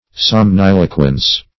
Search Result for " somniloquence" : The Collaborative International Dictionary of English v.0.48: Somniloquence \Som*nil"o*quence\, n. The act of talking in one's sleep; somniloquism.